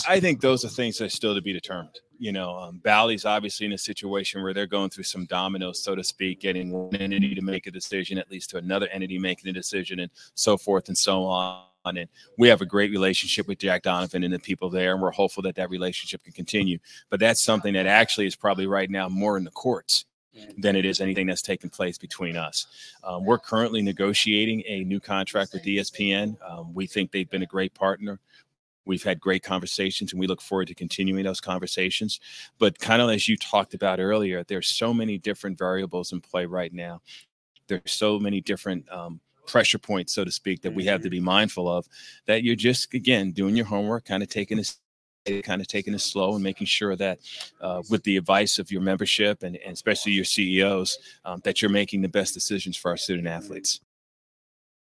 This conversation took place at the Valley men’s basketball media day.